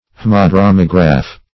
Search Result for " haemodromograph" : The Collaborative International Dictionary of English v.0.48: Haemodromograph \H[ae]m`o*drom"o*graph\ (-dr[-o]*m[o^]m"[-e]*t[~e]r), n. Same as H[ae]madromograph .
haemodromograph.mp3